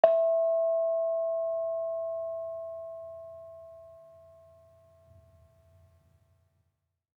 Gamelan Sound Bank
Bonang-E4-f.wav